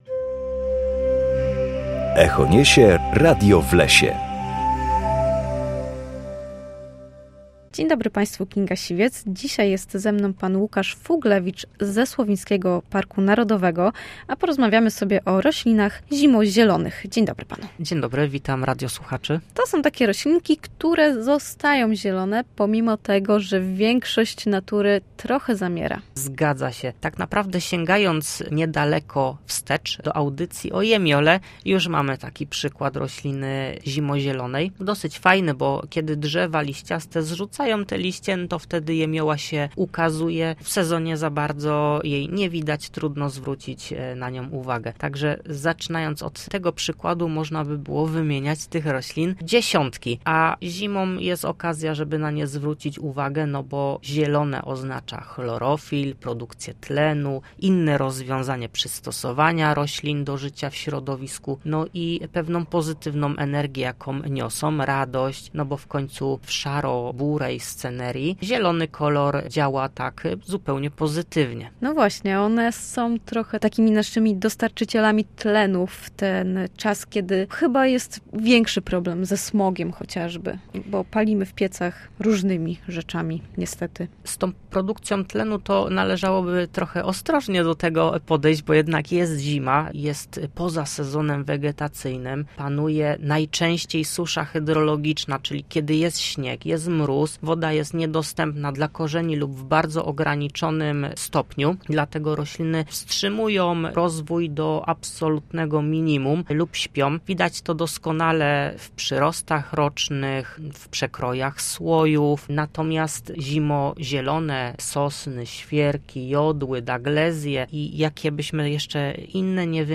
W każdą środę o godzinie 7:20 oraz o godzinie 14:10 na antenie Studia Słupsk rozmawiamy o naturze i sprawach z nią związanych.